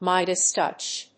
アクセントMídas tòuch